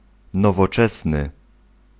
Ääntäminen
US RP : IPA : /ˈmɒd(ə)n/ GenAm: IPA : /ˈmɑdɚn/